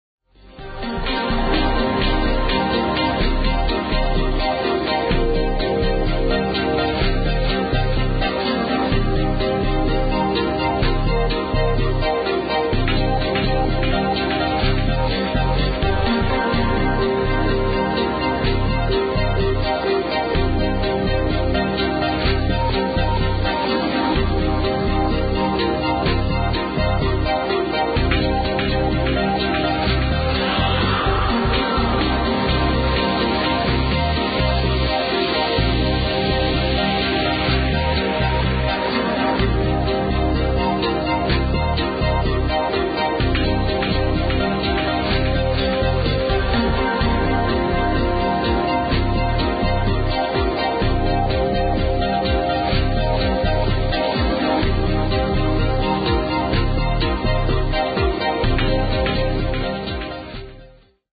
prog tune needs ID #1